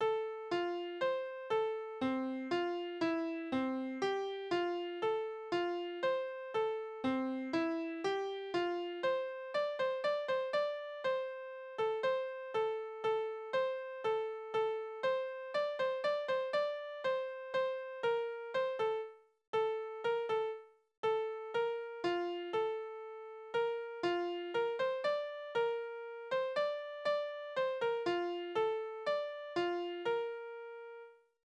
Tonart: F-Dur
Taktart: 3/4 (nicht vorgezeichnet)
Tonumfang: große None
Besetzung: vokal